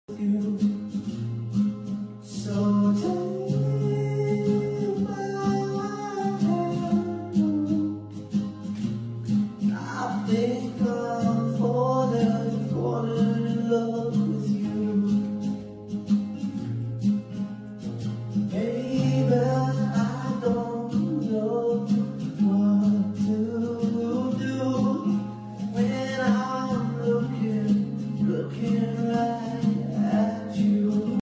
Jam Session